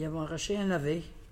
Localisation Saint-Christophe-du-Ligneron
Catégorie Locution